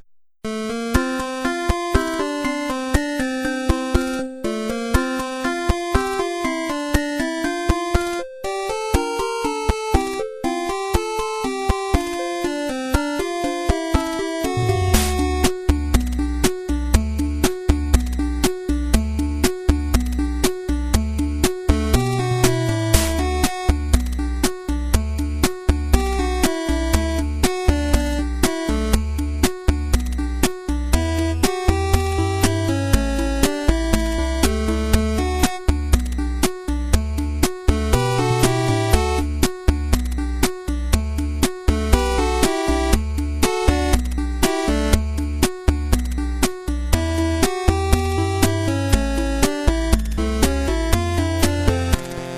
8-Bit